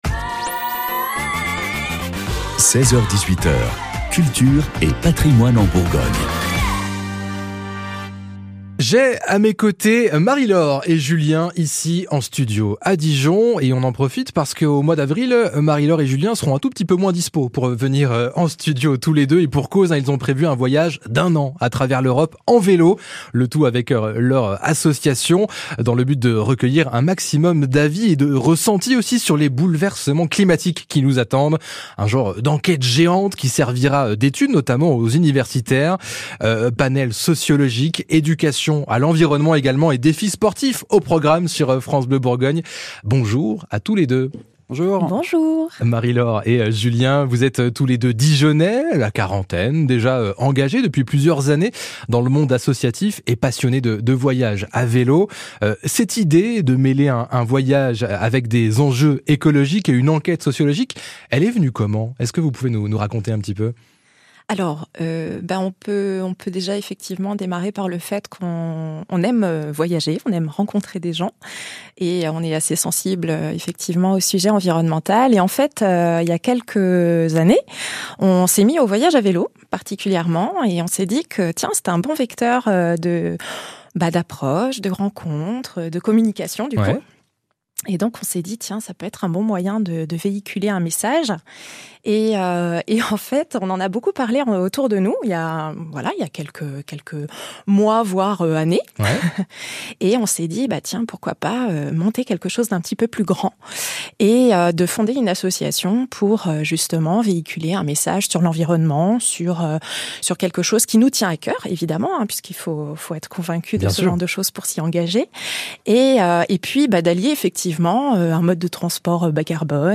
étaient les invités de France Bleu Bourgogne